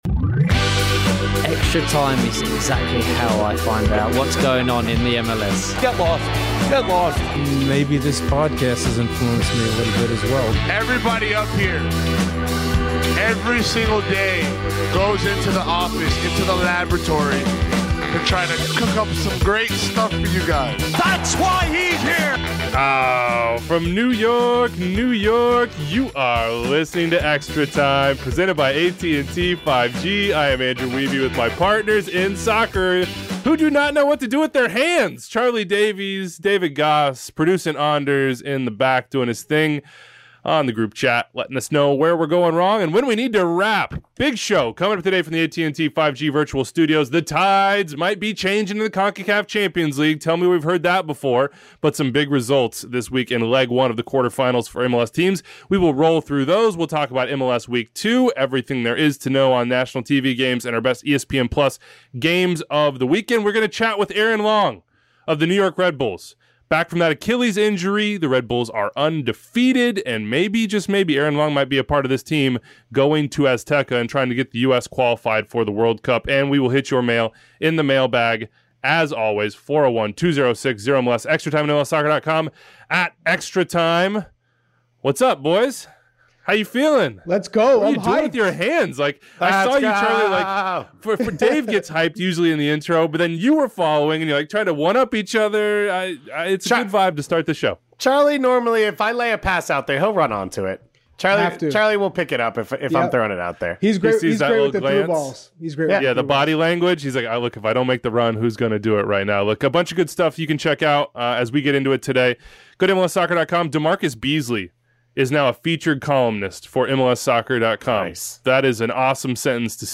53:14 - Aaron Long Interview